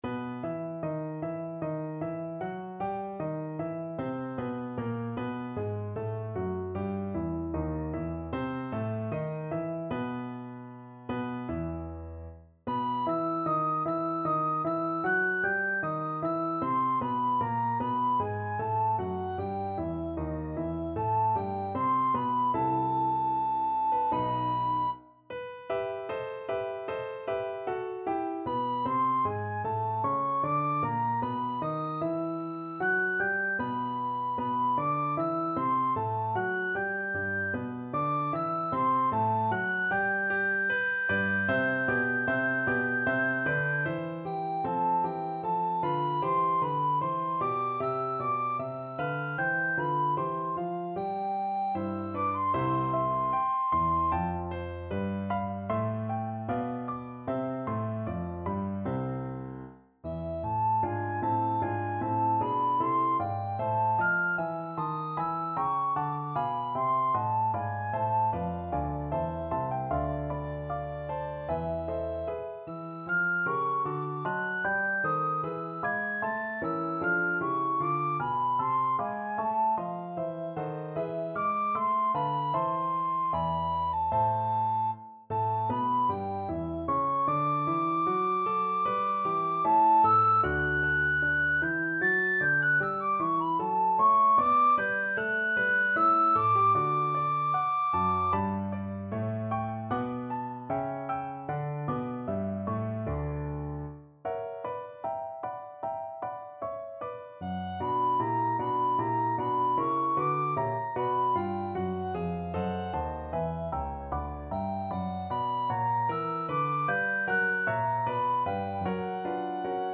Classical Handel, George Frideric The People that Walked in Darkness from Messiah Soprano (Descant) Recorder version
4/4 (View more 4/4 Music)
G major (Sounding Pitch) (View more G major Music for Recorder )
Larghetto (=76)
Classical (View more Classical Recorder Music)